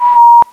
Index of /m8-backup/M8/Samples/Fairlight CMI/IIX/CHORAL
WHIST2.WAV